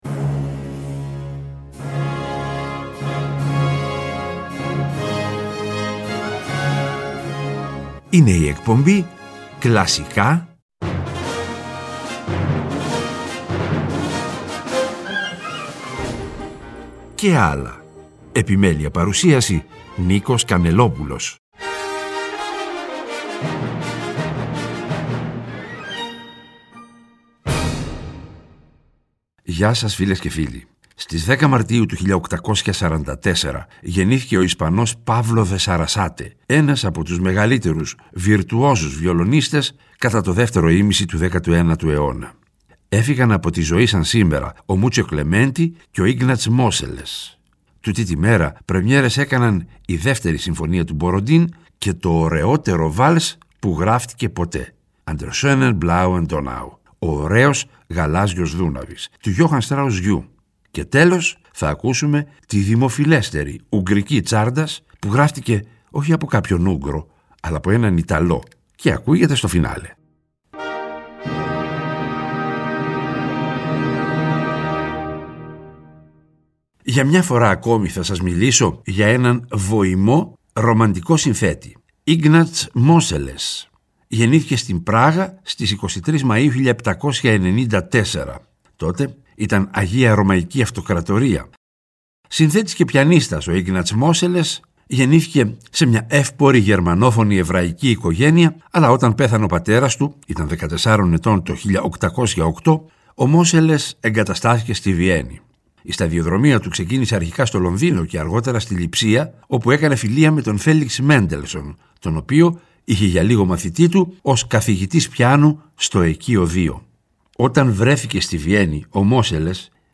Η δημοφιλέστερη ουγγρική τσάρντας γράφτηκε από έναν Ιταλό (!) και ακούγεται στο φινάλε.